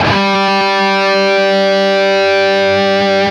LEAD G 2 CUT.wav